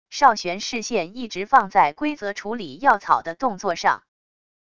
邵玄视线一直放在归泽处理药草的动作上wav音频生成系统WAV Audio Player